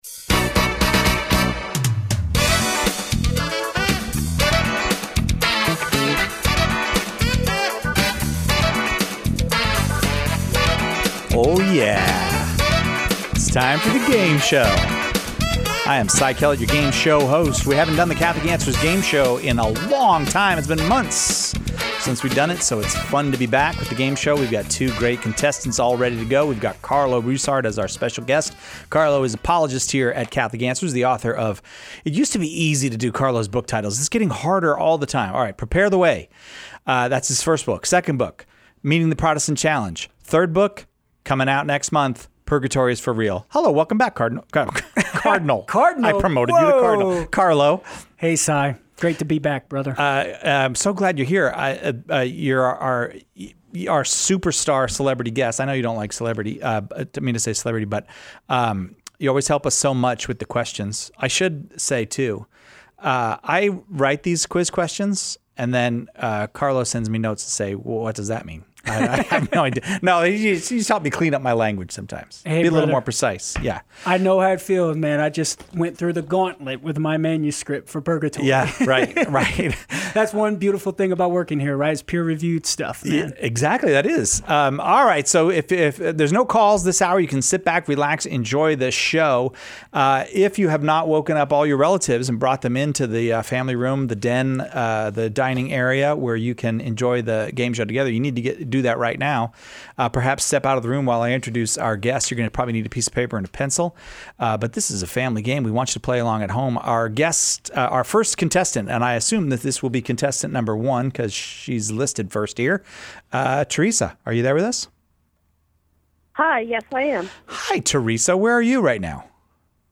Once a month, Catholic Answers Live listeners play Catholic trivia, battling to win books and courses from Catholic Answers’ School of Apologetics.